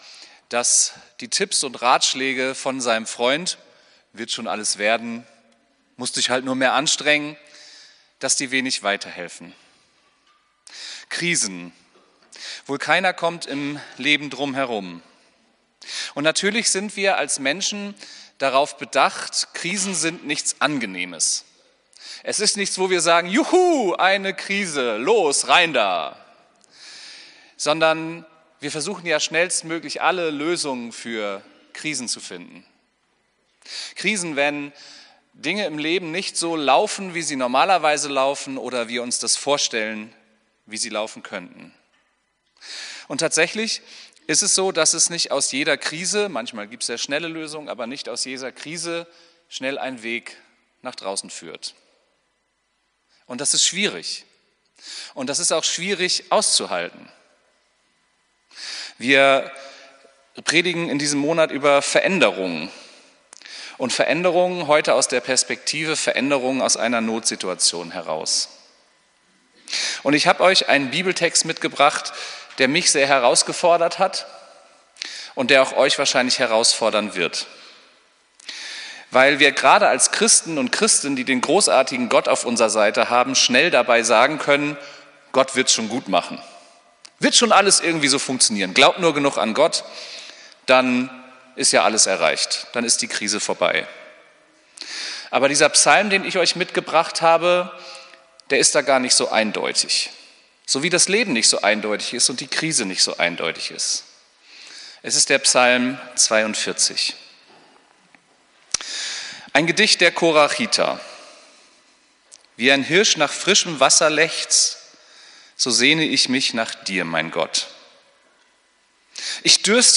Predigt vom 23.03.2025